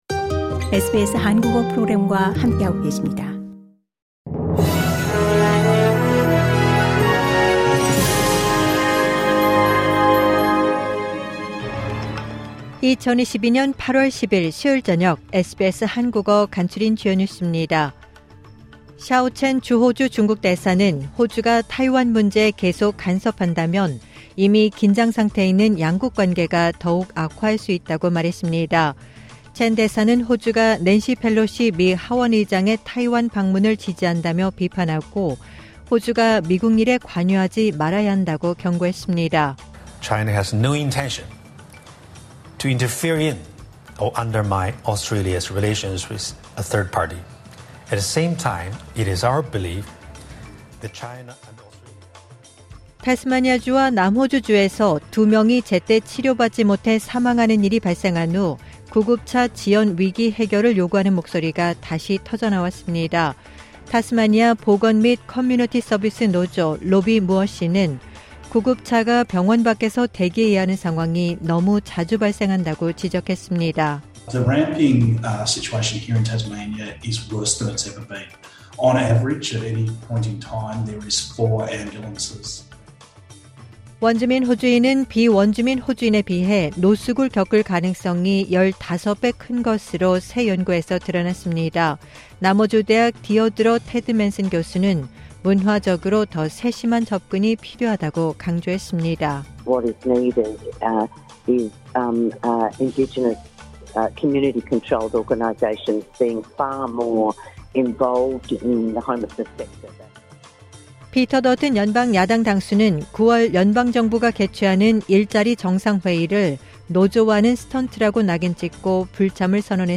SBS 한국어 저녁 뉴스: 2022년 8월 10일 수요일